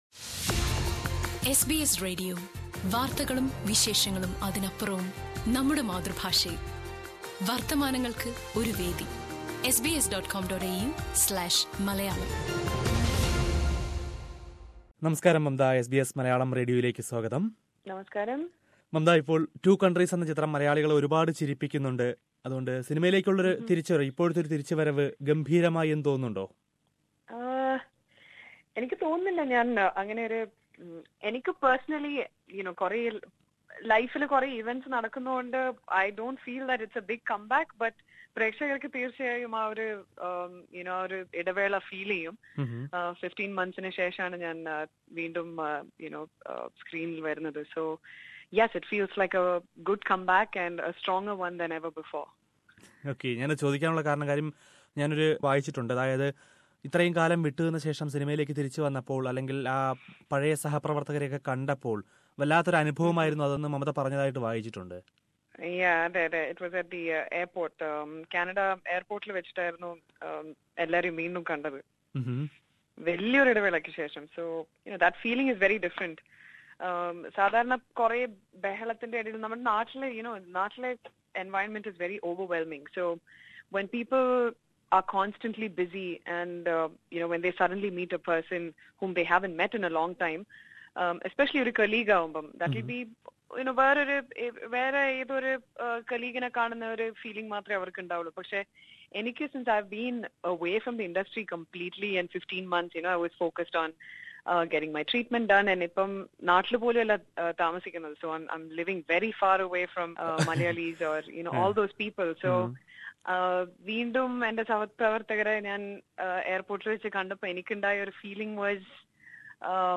Chit chat with Mamta Mohandas
After the success of the movie Two Countries, actress Mamta Mohandas talks to SBS Malayalam Radio - not only about the film, but about her recovery from cancer, friends in the film filed etc. Listen to it from the player above.